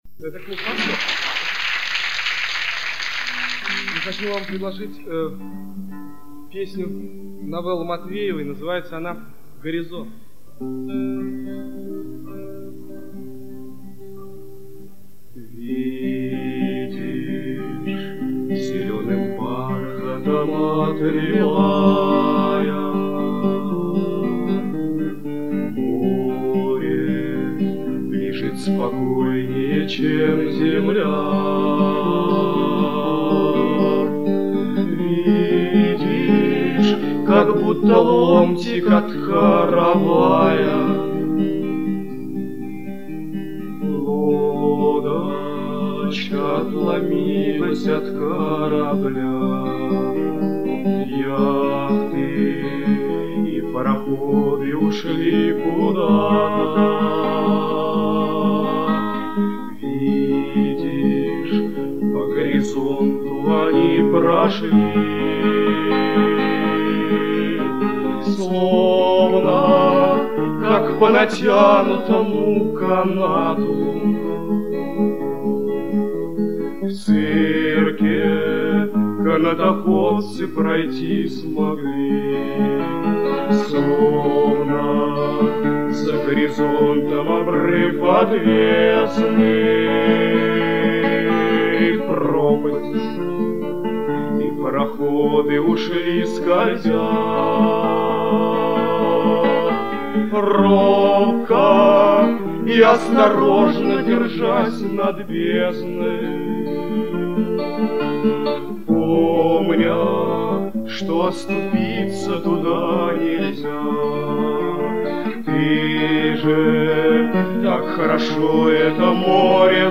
Привожу для примера несколько песен в исполнении дуэта